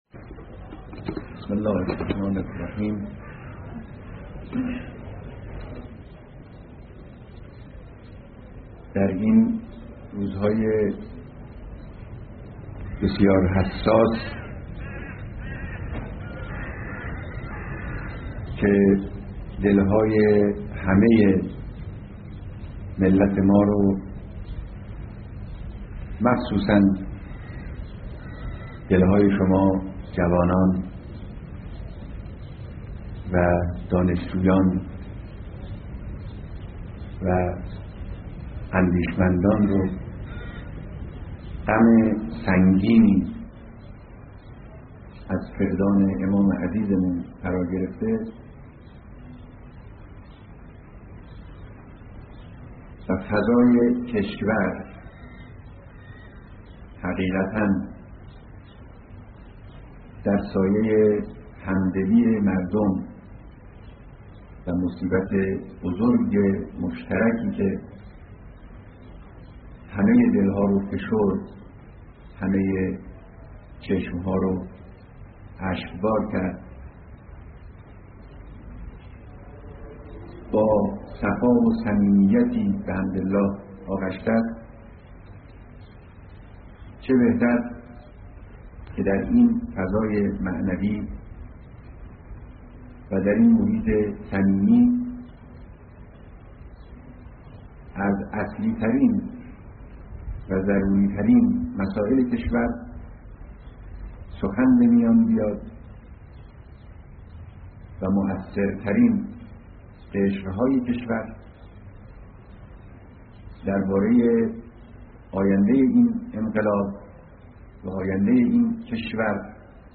سخنرانی در مراسم بیعت طلاب و اساتید حوزهی علمیهی قم و روحانیون بیست کشور جهان